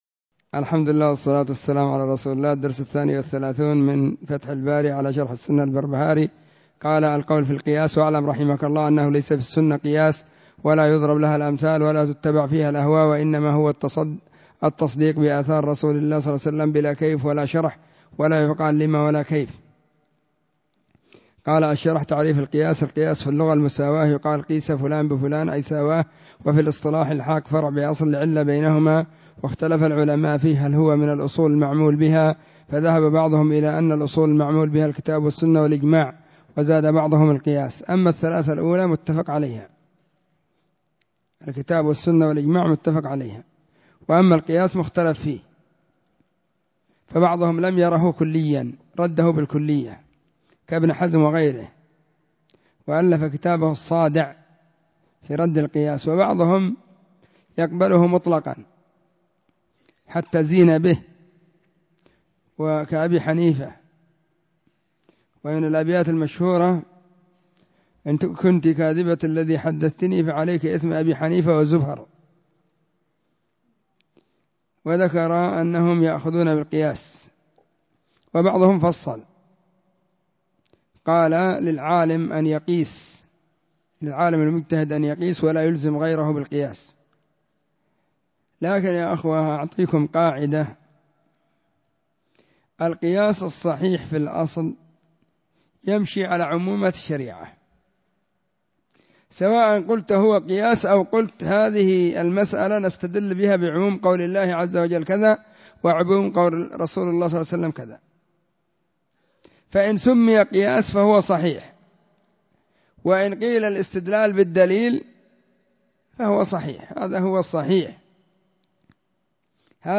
الدرس 32 من كتاب فتح الباري على شرح السنة للبربهاري
📢 مسجد الصحابة – بالغيضة – المهرة، اليمن حرسها الله.